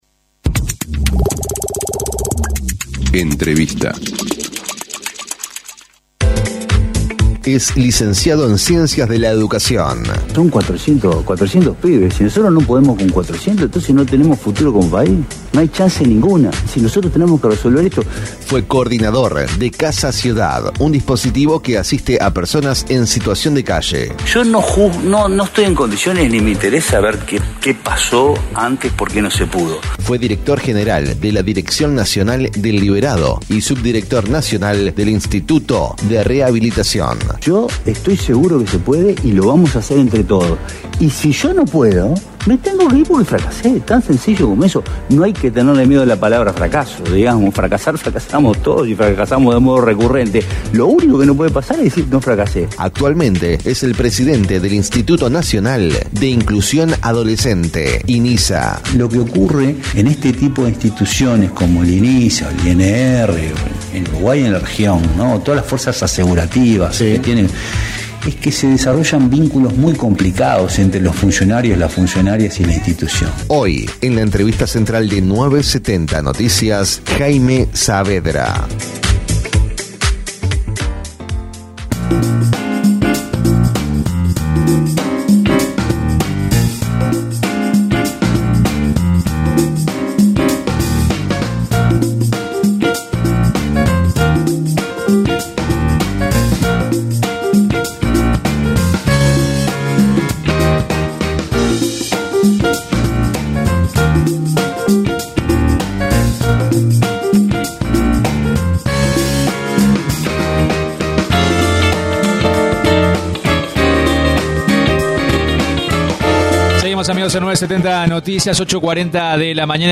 El presidente del Instituto Nacional de Inclusión Social Adolescente (INISA), Jaime Saavedra, se refirió, en diálogo con 970 Noticias, a una «comisión» que ya está «trabajando a todo trapo», y que va a encargarse del armado de la carrera funcional y administrativa de la institución.